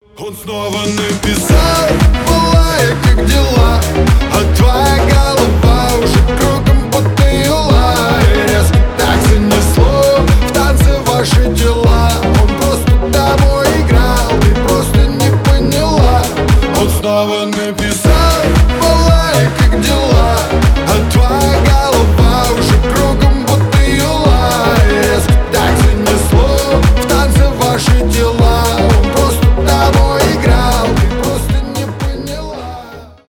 2025 » Новинки » Русские » Рэп Скачать припев